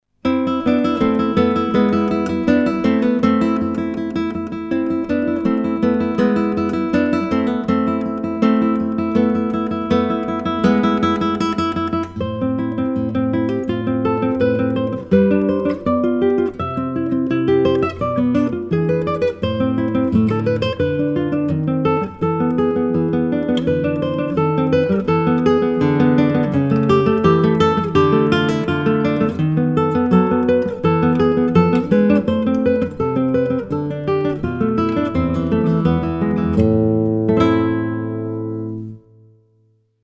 Hauser 1937 style guitar
European Spruce soundboard, Indian Rosewood back & sides-